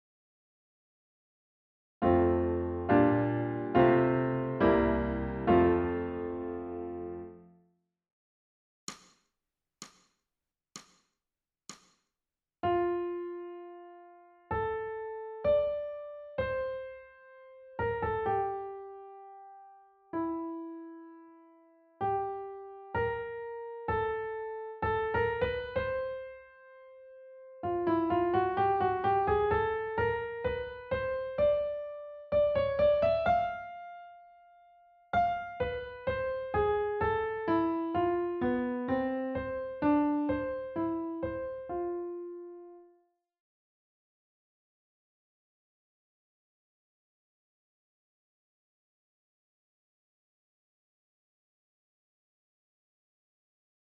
ソルフェージュ 聴音: 2-0-09